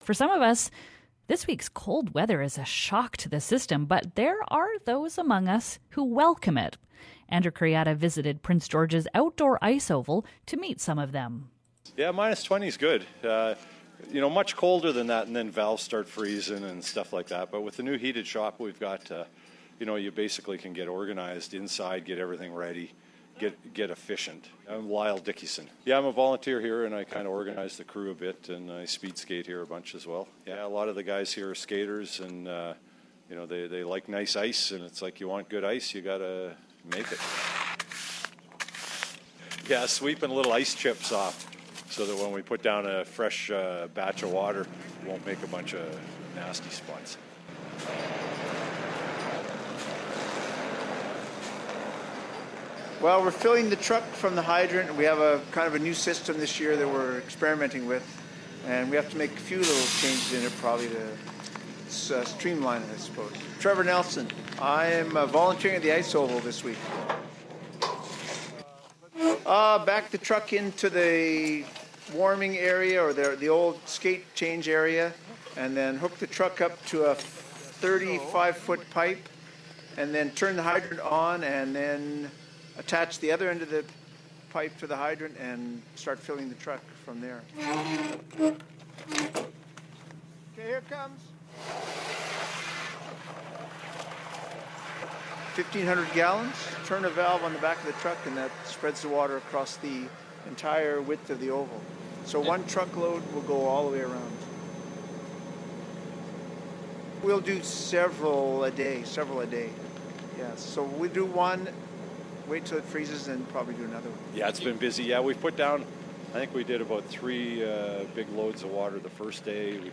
We take a trip to the outdoor ice oval in Prince George to hear the hard work of preparing ice.